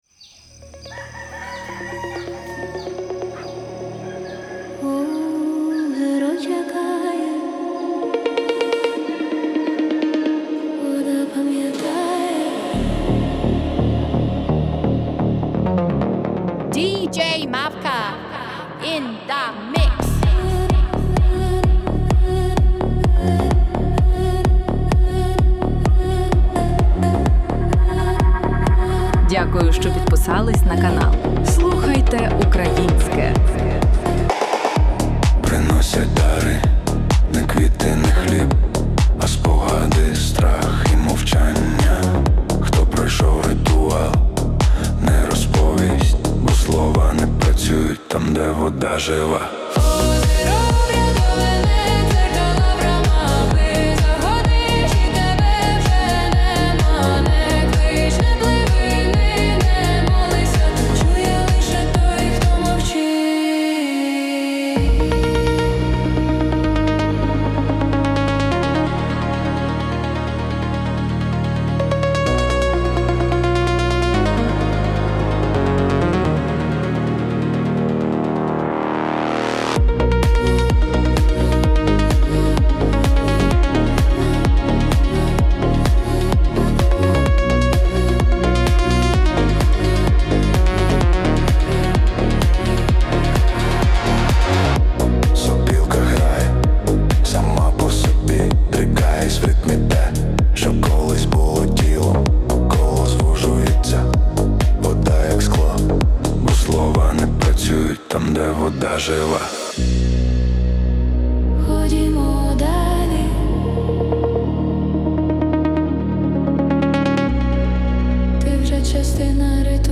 Deep House Ukrainian Electronic Folk Треклист: 1.